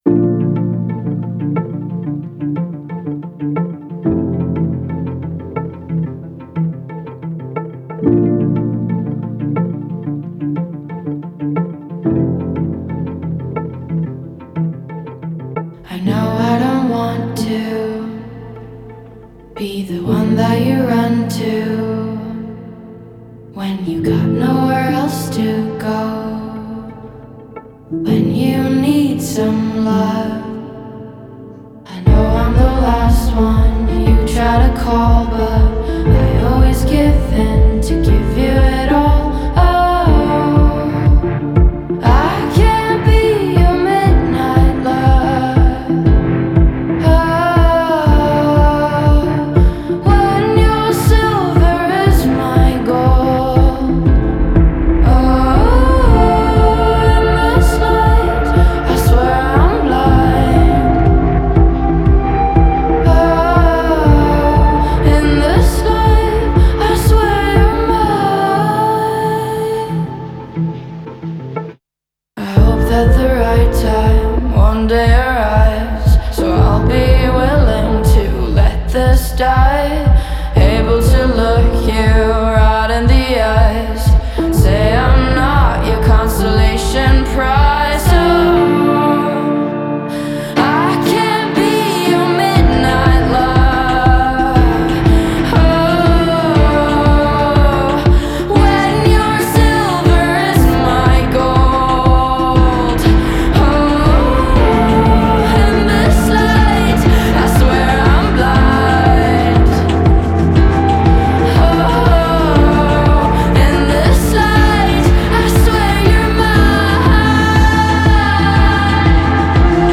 Dream Pop